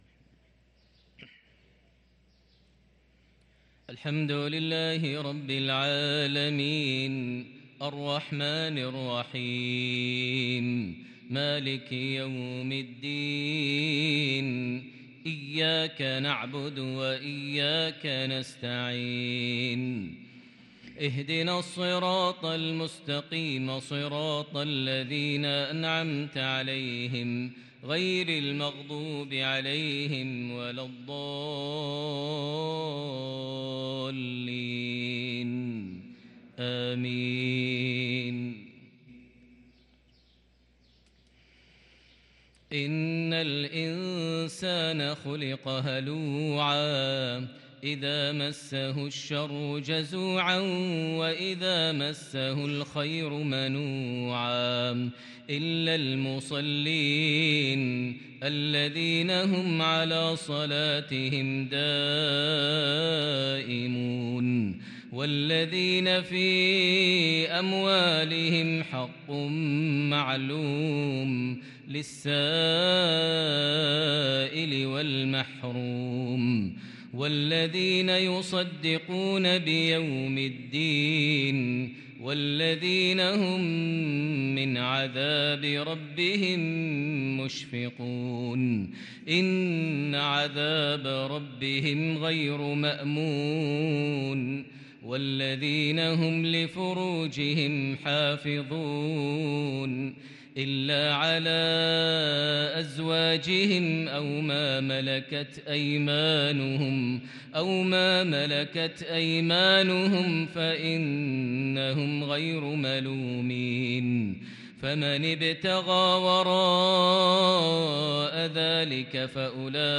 صلاة المغرب للقارئ ماهر المعيقلي 10 جمادي الآخر 1444 هـ
تِلَاوَات الْحَرَمَيْن .